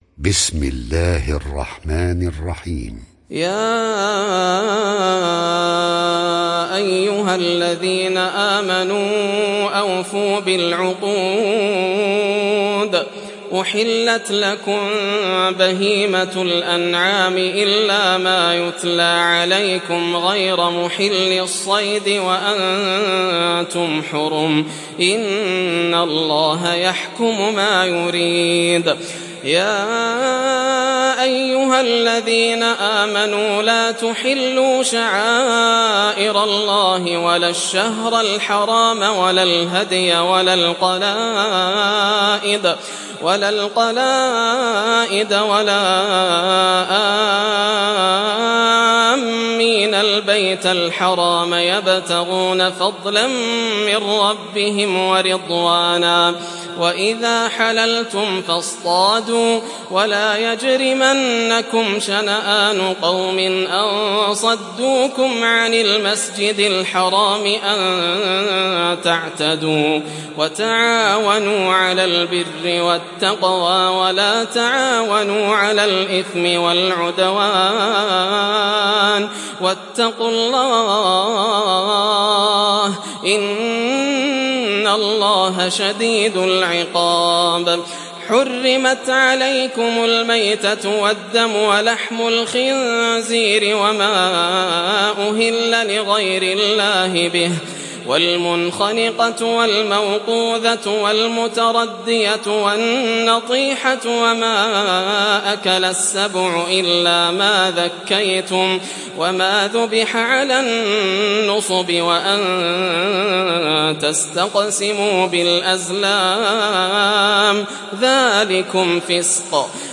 Sourate Al Maidah Télécharger mp3 Yasser Al Dosari Riwayat Hafs an Assim, Téléchargez le Coran et écoutez les liens directs complets mp3